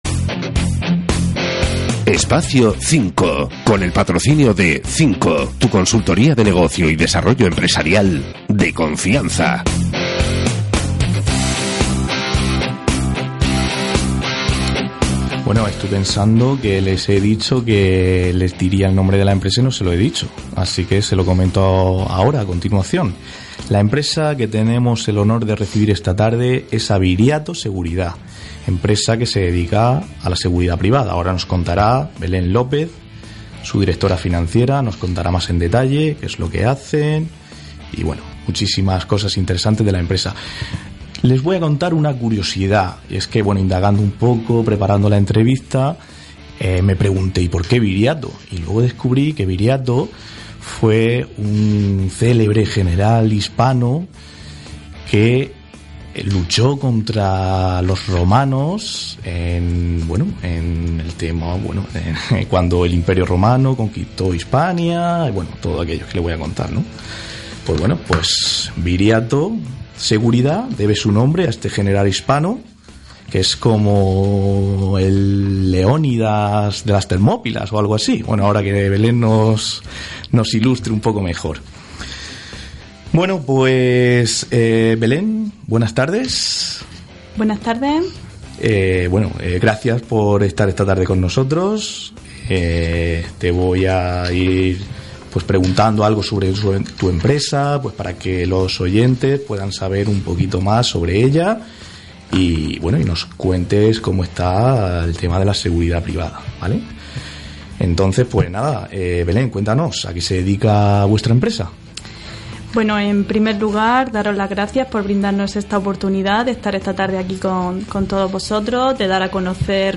Os presentamos la entrevista realizada a Viriato Seguridad en Que!Radio